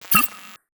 UI_SFX_Pack_61_20.wav